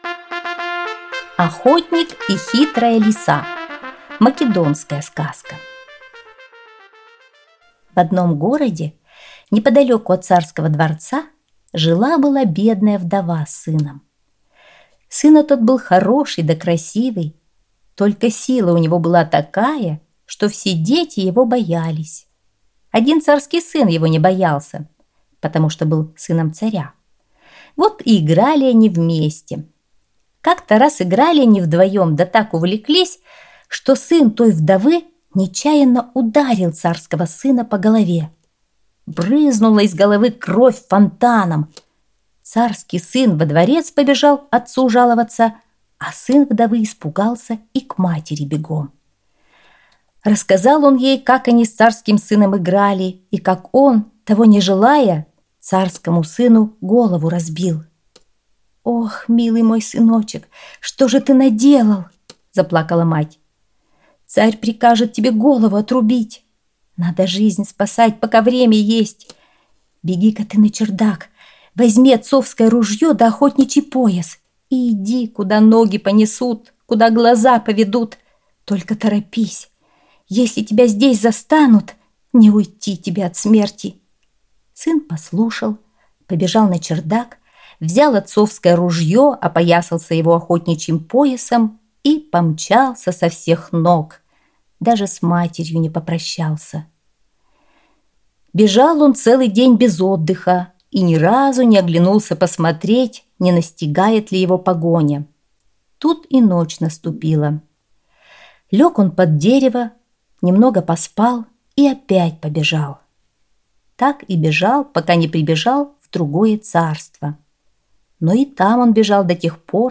Охотник и хитрая лиса - македонская аудиосказка - слушать